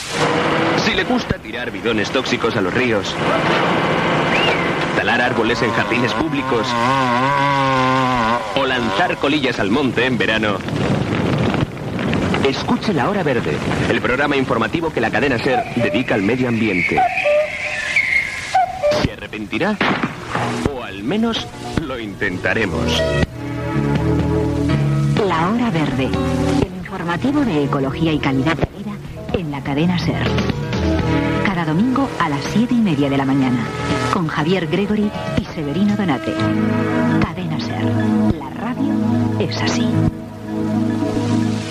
Gravació realitzada a València.